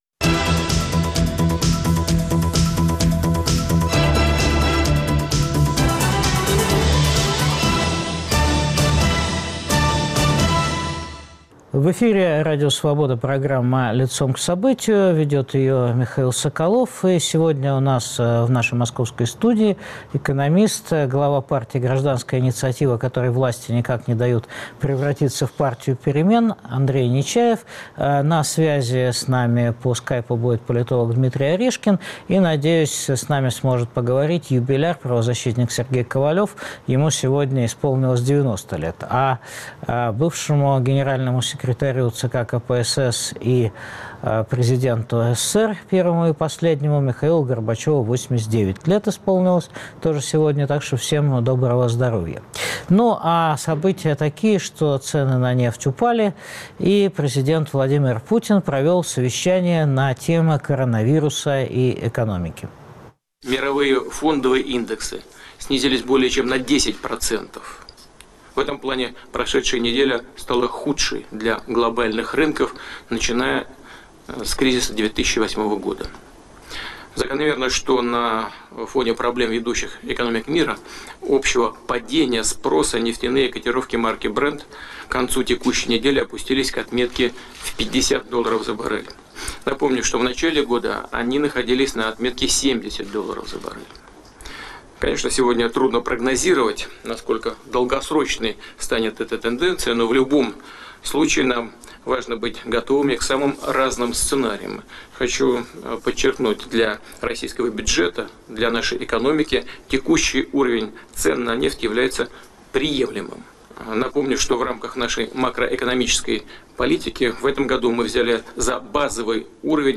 Позволят ли мировые тренды сохранить Россию в ее нынешнем состоянии? В день рождения Михаила Горбачева шансы политической модернизации России обсудят экономист Андрей Нечаев, политолог Дмитрий Орешкин, правозащитник Сергей Ковалев, которому сегодня исполнилось 90 лет.